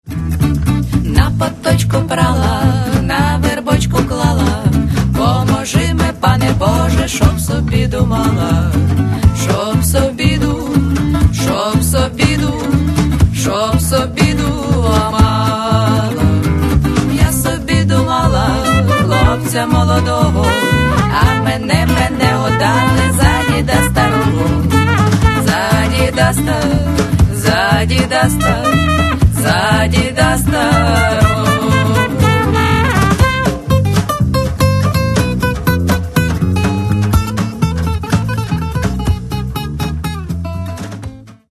Каталог -> Рок и альтернатива -> Фольк рок